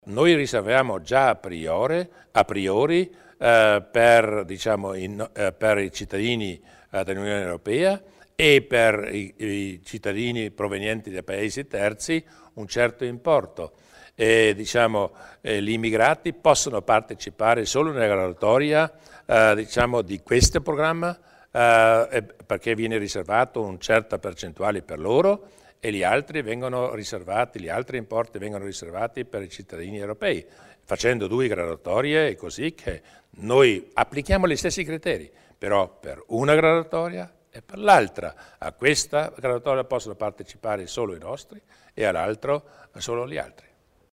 Il Presidente Durnwalder spiega il sistema delle graduatorie per l'assegnazione di alloggi e contributi in edilizia